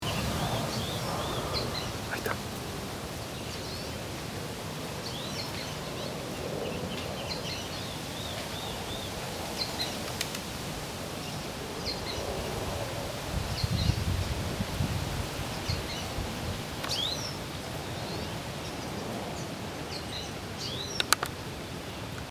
Sooty-fronted Spinetail (Synallaxis frontalis)
Life Stage: Adult
Location or protected area: Reserva Ecológica Costanera Sur (RECS)
Condition: Wild
Certainty: Recorded vocal